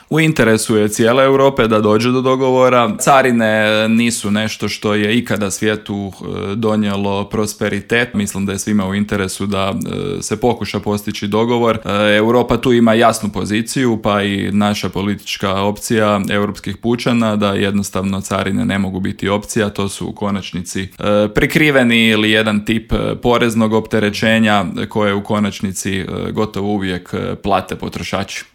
ZAGREB - "Europa je godinama zanemarivala pitanje obrane i sigurnosti i to se sada mijenja iz temelja jer naš odgovor ne može biti samo deklaratoran nego i konkretan", u Intervjuu Media servisa poručio je eurozastupnik iz redova HDZ-a Karlo Ressler.